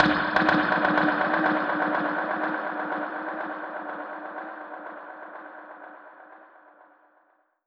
Index of /musicradar/dub-percussion-samples/125bpm
DPFX_PercHit_C_125-01.wav